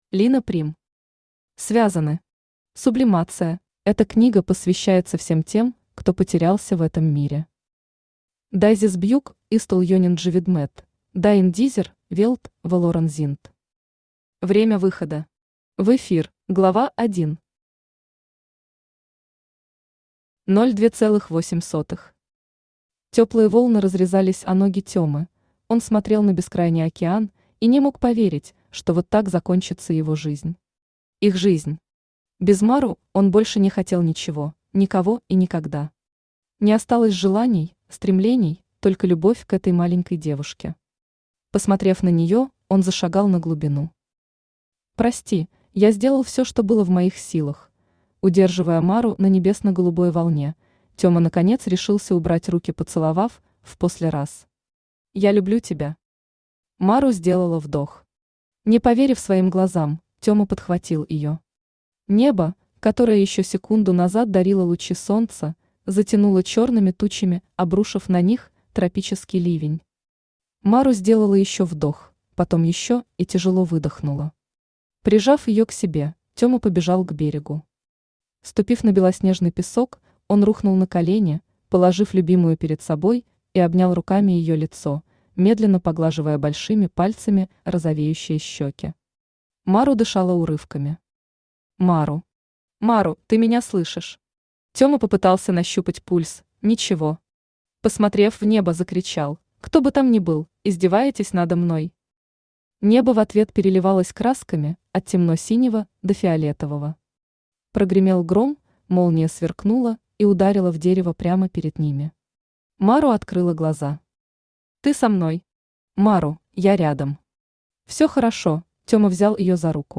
Аудиокнига Связаны. Сублимация | Библиотека аудиокниг
Сублимация Автор Лина Прим Читает аудиокнигу Авточтец ЛитРес.